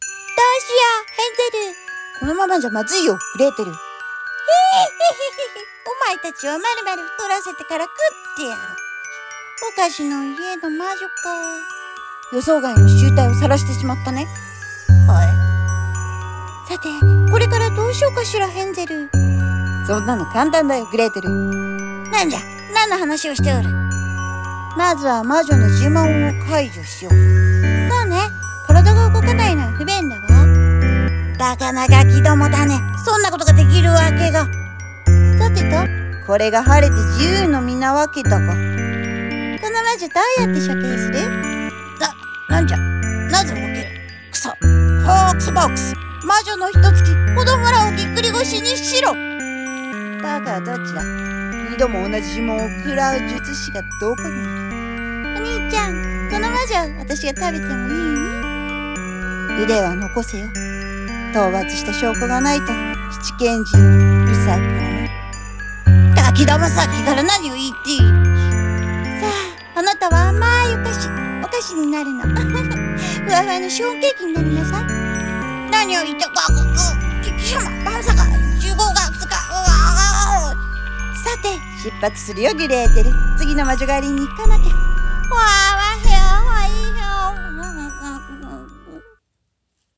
さんの投稿した曲一覧 を表示 【声劇】ヘンゼルとグレーテル【3人声劇】 と、見せかけて、ぼっち劇😆